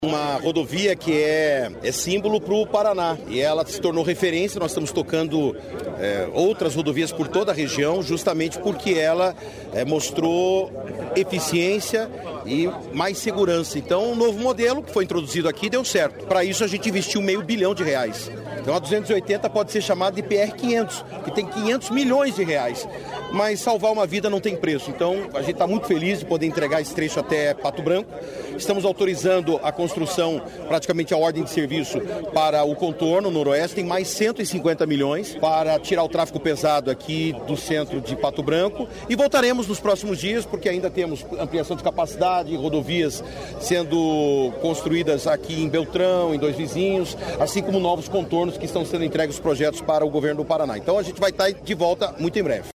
Sonora do secretário de Infraestrutura e Logística, Sandro Alex, sobre modernização da PRC-280